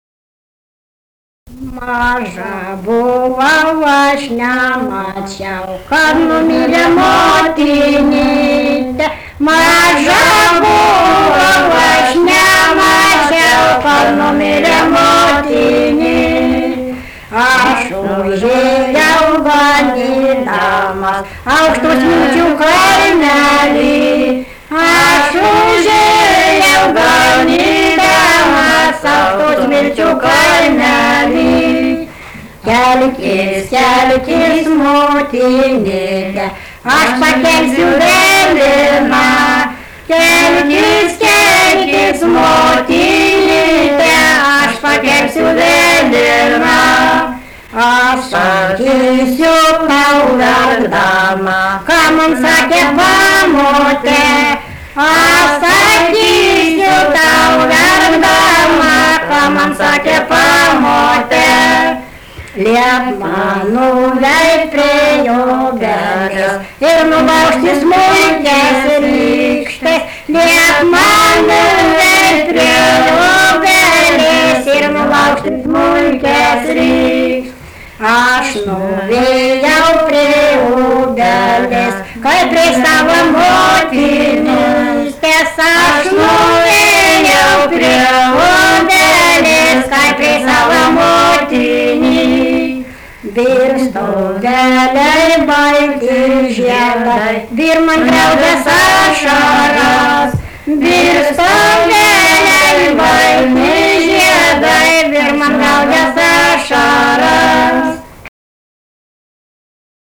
daina, šeimos
Gaižaičiai
vokalinis